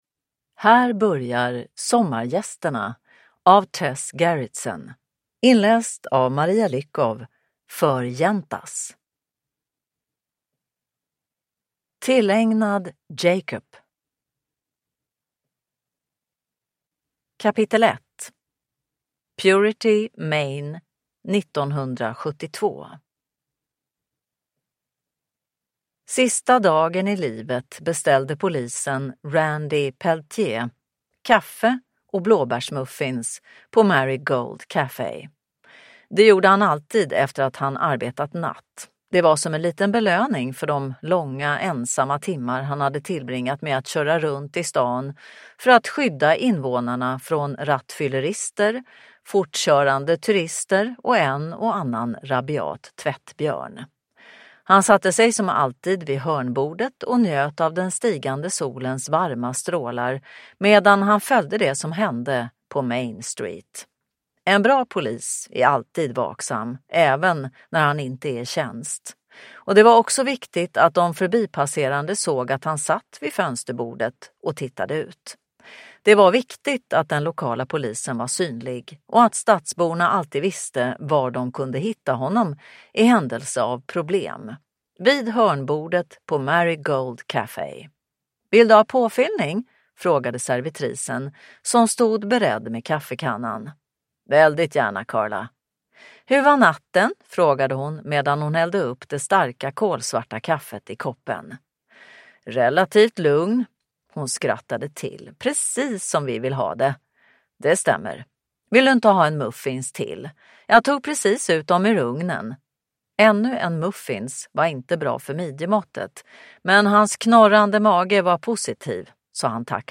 Sommargästerna – Ljudbok